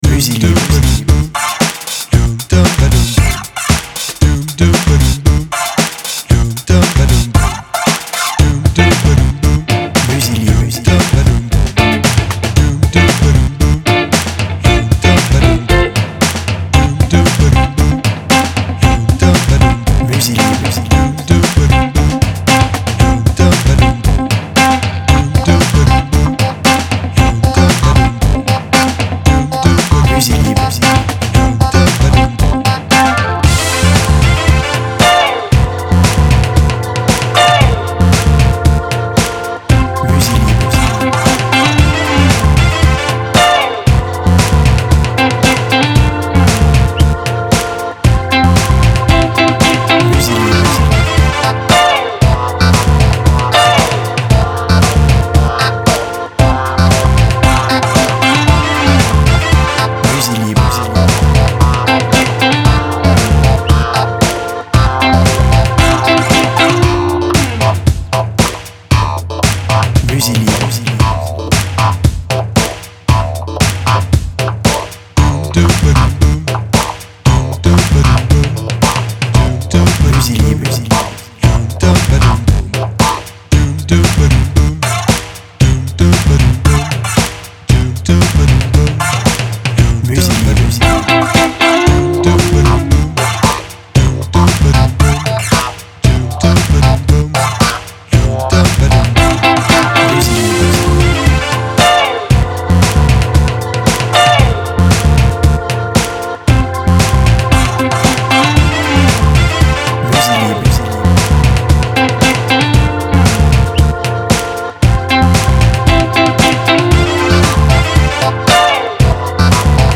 BPM Rapide